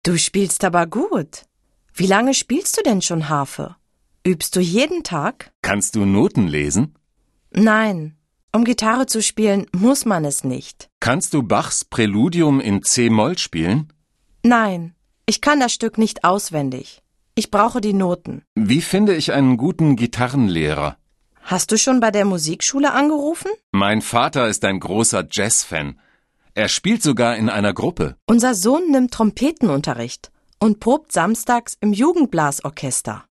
Un peu de conversation - La musique